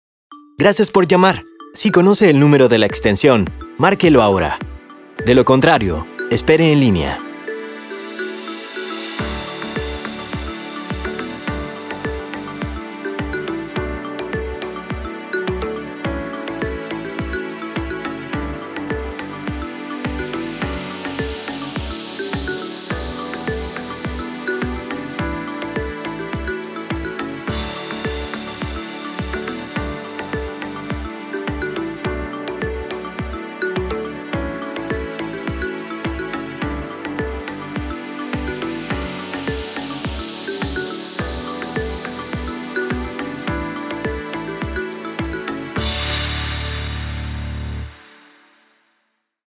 Locuciones para centrales telefónicas
A continuación te ofrezco tres locuciones para centrales telefónicas, están diseñadas para que tus clientes no se desesperen al momento de estar en el teléfono. Estos mensajes de IVR son totalmente gratuitos para usar en tu centralita o central telefónica.